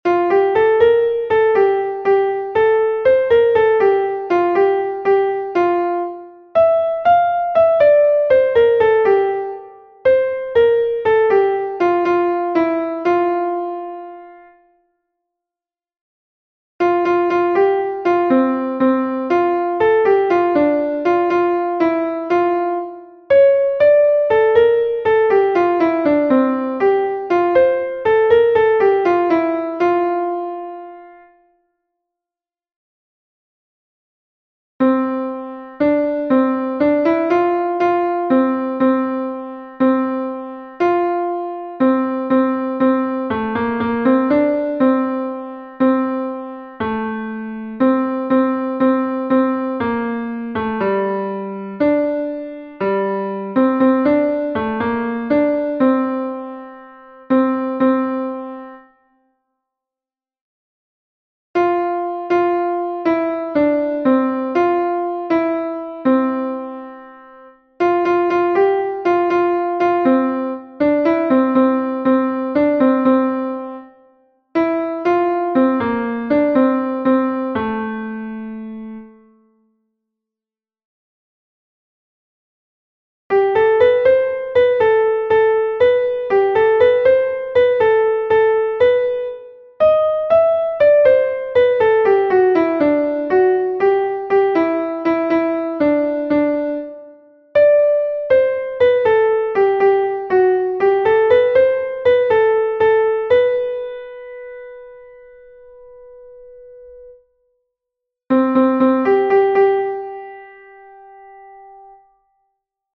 Help for altos:
let_all_the_rivers-alto.mp3